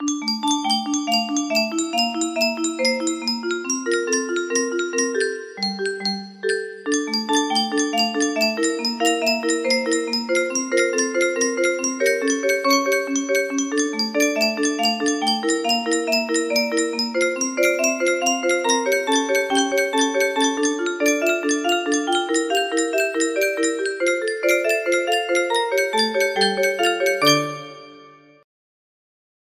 golden age bit for curiosity's sake [more bottom notes] music box melody
Full range 60
i was interested to see if this ascending line method would work in a music box, and it did!